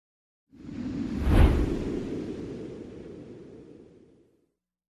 NUEVA BRISABRISA DESERTICA VIENTO
Ambient sound effects
Nueva_brisabrisa_desertica_viento.mp3